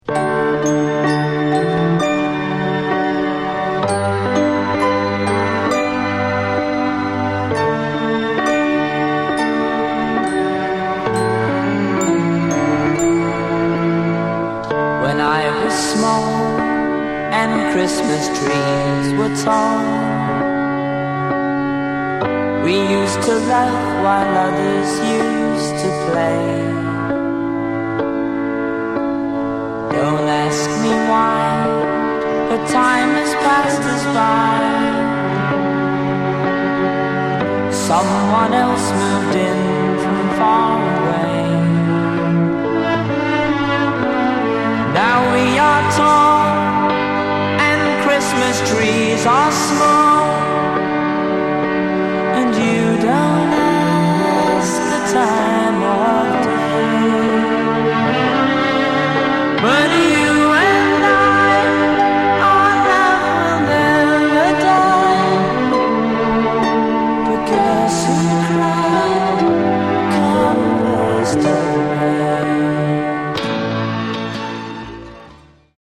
It has Mint labels and pristine sound.